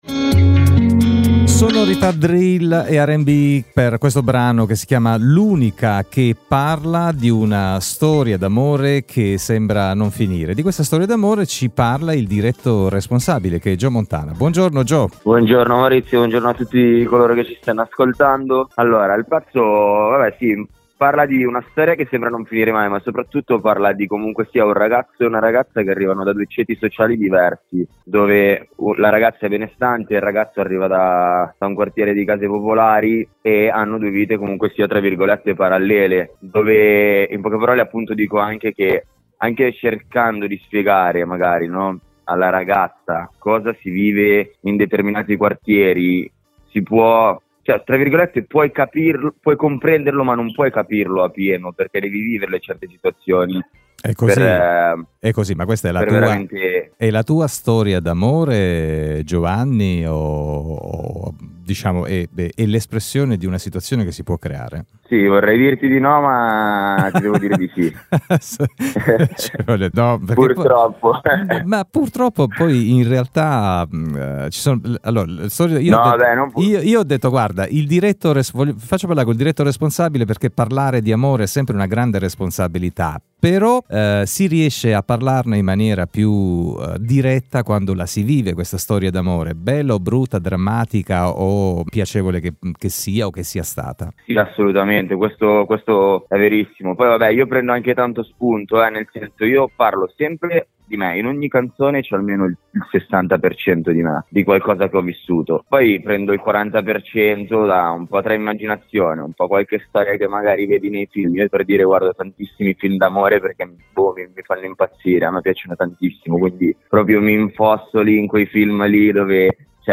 Una bella chiacchierata